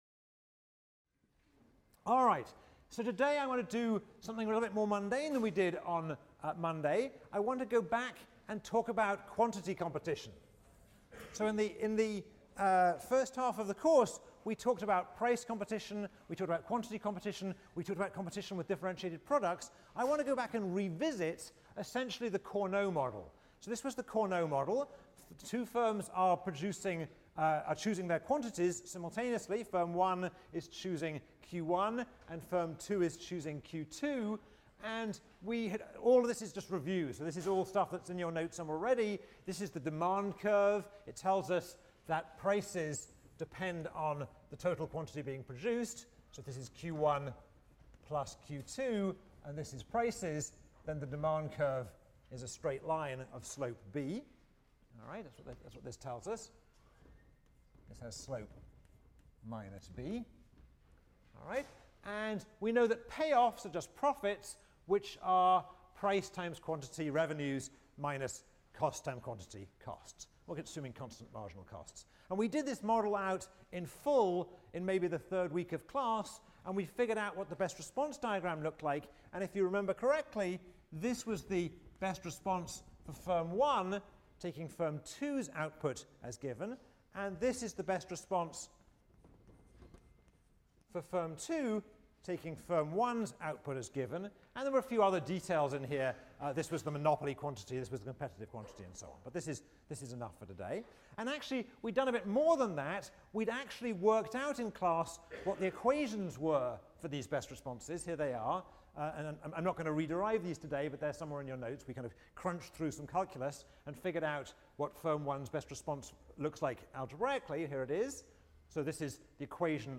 ECON 159 - Lecture 14 - Backward Induction: Commitment, Spies, and First-Mover Advantages | Open Yale Courses